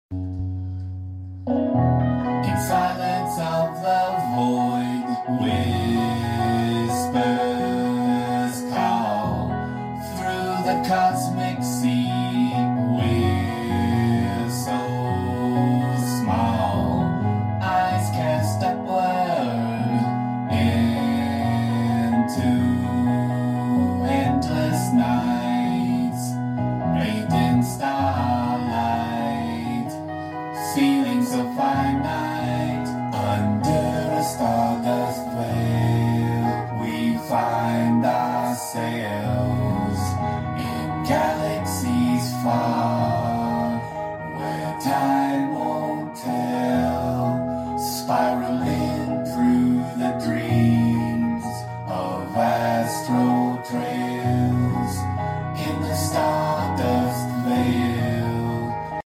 Working on tightening up the vocals then we're ready to go!